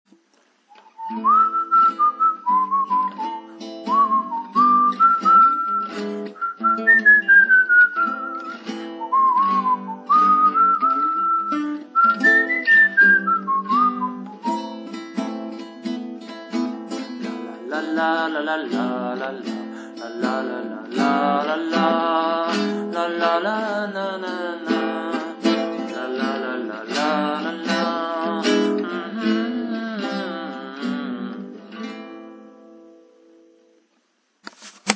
: en ré majeur
la mesure est une mesure 3/4.